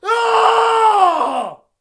battle-cry-2.ogg